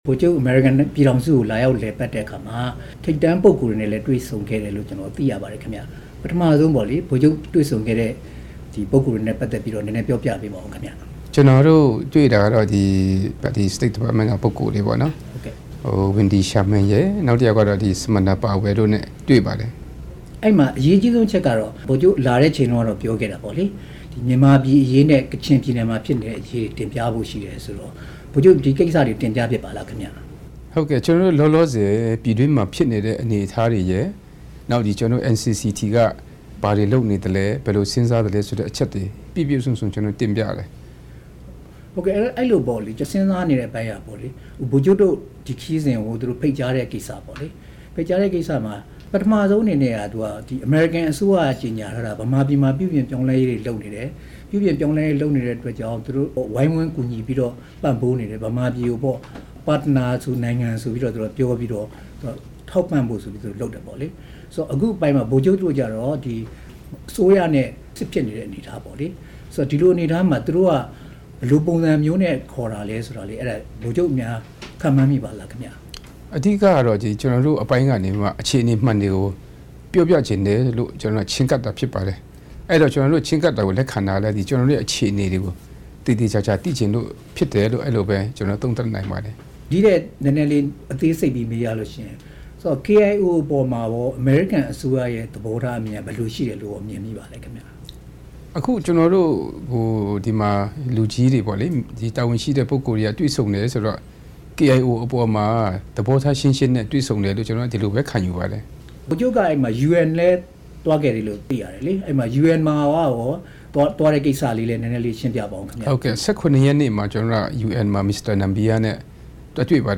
KIA ဒုတိယကာကွယ်ရေးဦးစီးချုပ် ဗိုလ်ချုပ် ဂွမ်မော် နဲ့ မေးမြန်းချက်